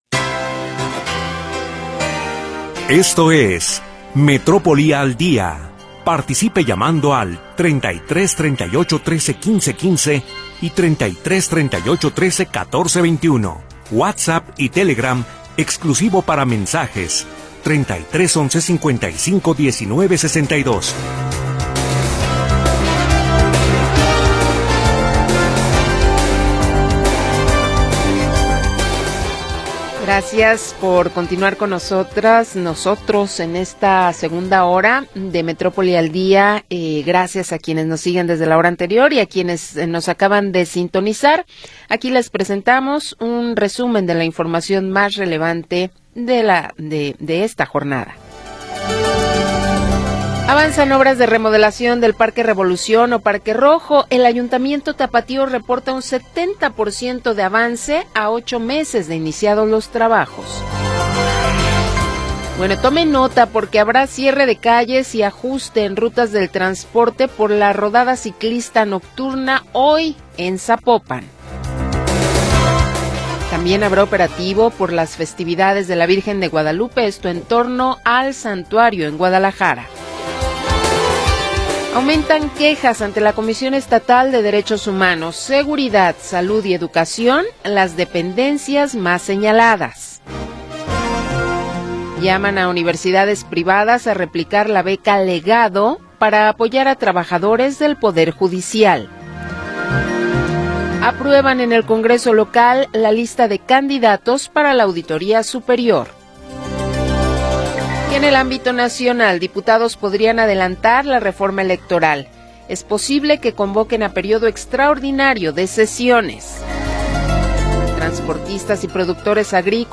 1 Metrópoli al Día 2da Hora - 20 de Noviembre de 2024 44:51 Play Pause 10h ago 44:51 Play Pause Reproducir más Tarde Reproducir más Tarde Listas Me gusta Me gusta 44:51 La historia de las últimas horas y la información del momento. Análisis, comentarios y entrevistas